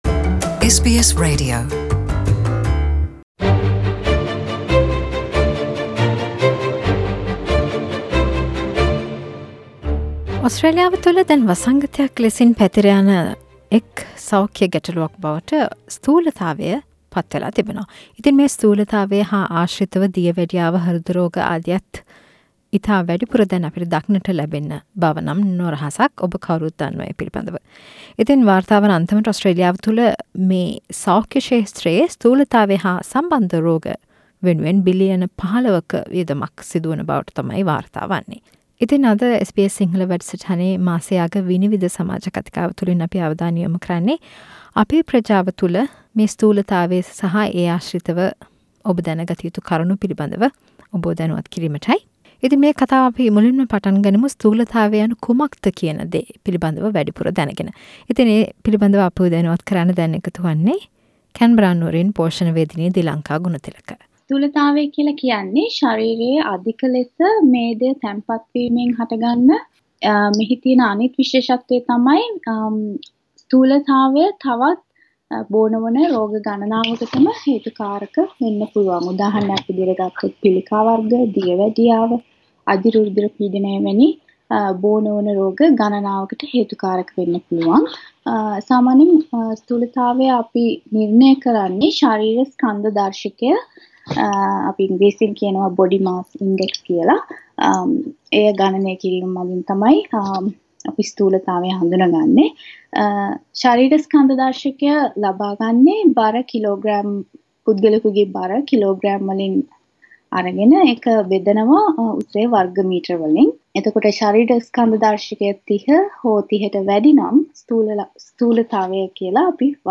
මෙවර SBS සිංහල විනිවිද සමාජ කතිකාව තුලින් අවධානයට ලක් කලේ ඕස්ට්‍රේලියාවේ වෙසෙන සංක්‍රමණික ප්‍රජාවට ස්තුලතාවයෙන් මිදිය හැකි අයුරු